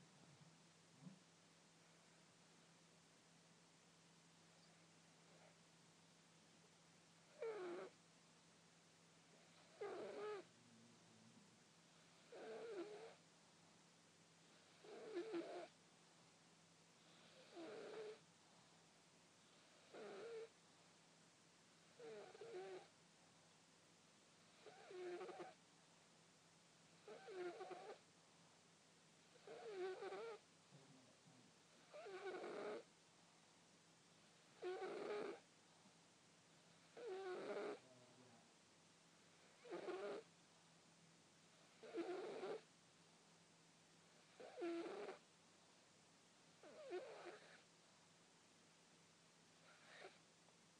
Our wheezy cat
Kitty is getting noisier when she sleeps.